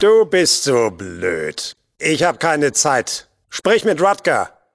Fallout: Audiodialoge